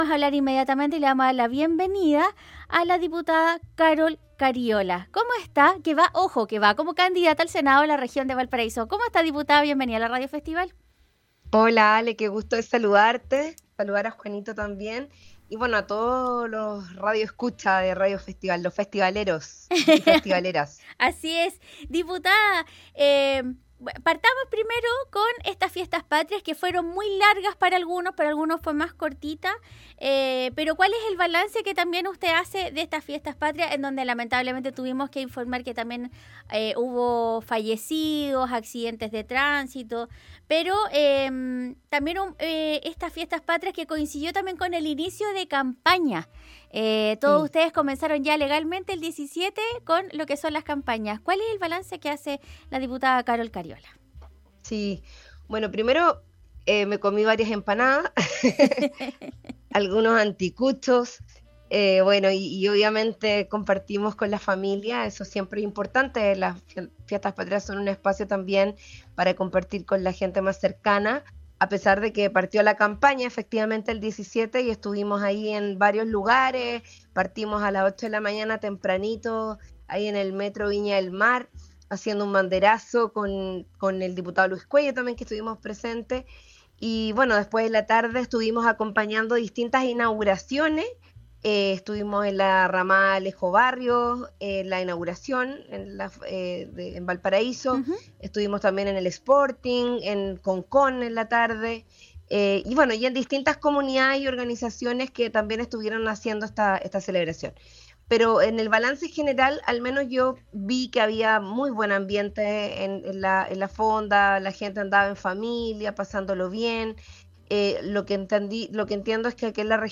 La actual Diputada conversó con Radio Festival para hacer un balance de lo que fueron las Fiestas Patrias, así como el reingreso del proyecto del Royalty portuario